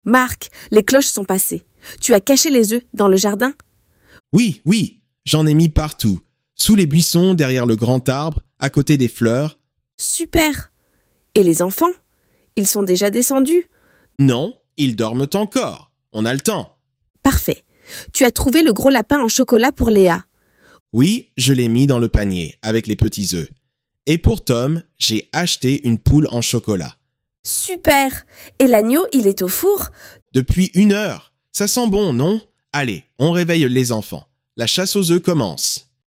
ElevenLabs_P--ques_Edu_MNC.mp3